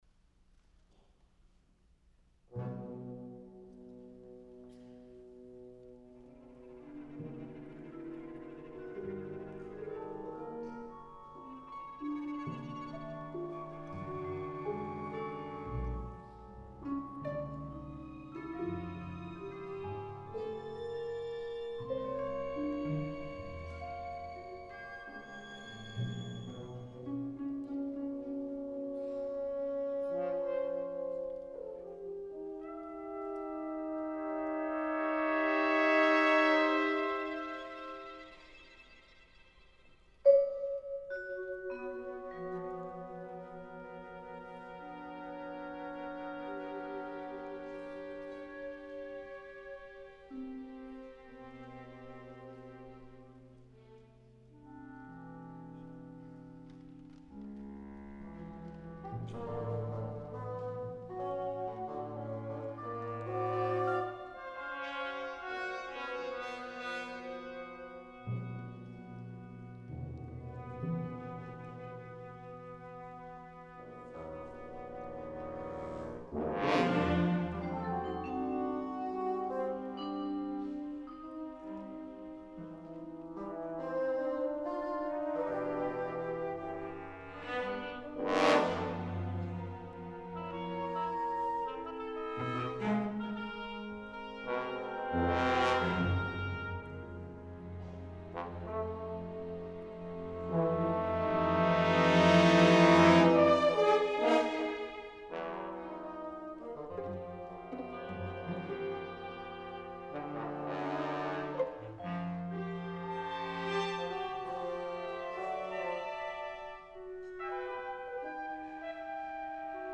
a work for orchestra